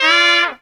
HARM RIFF 2.wav